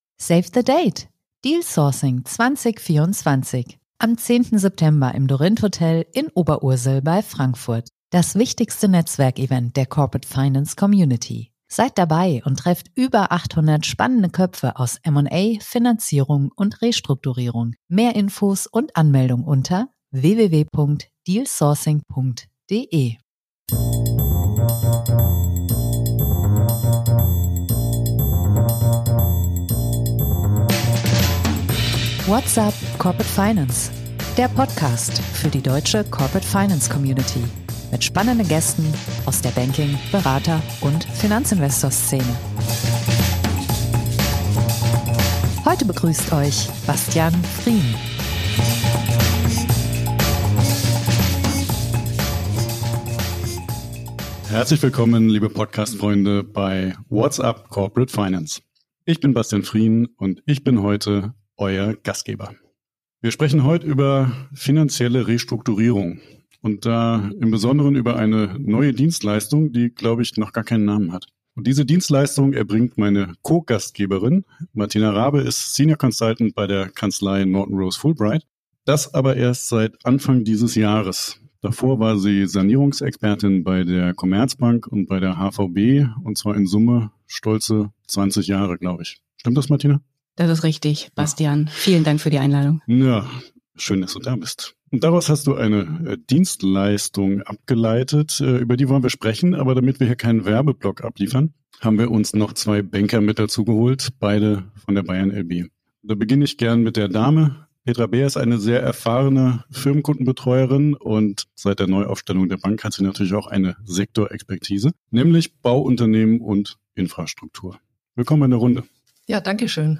Unsere drei Gesprächspartner glauben das, und darüber sprechen wir in diesem Podcast.